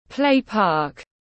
Khu vui chơi tiếng anh gọi là play park, phiên âm tiếng anh đọc là /ˈpleɪ ˌpɑːk/
Play-park.mp3